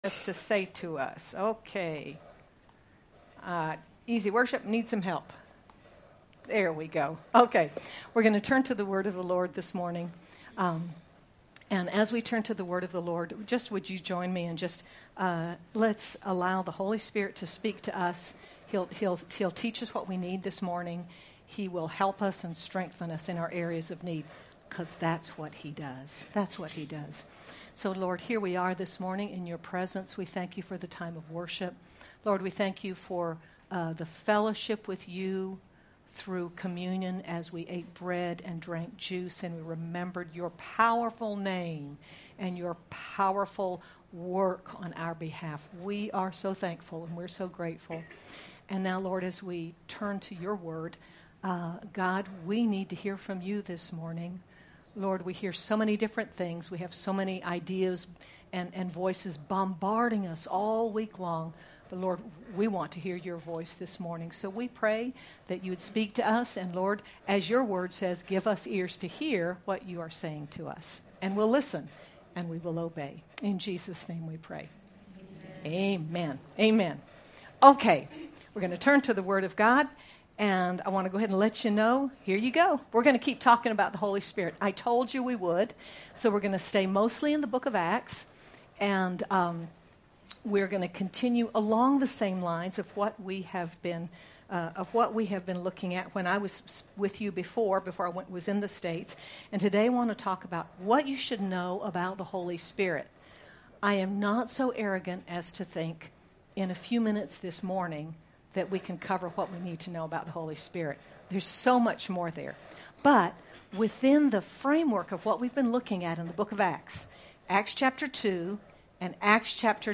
Jul 01, 2024 What You Should Know About the Holy Spirit MP3 SUBSCRIBE on iTunes(Podcast) Notes Discussion Sermons in this Series In this message when we look at the gift of the Holy Spirit on the Day of Pentecost and to Cornelius and the Gentiles, we see three essentials we should know about the Holy Spirit. Sermon by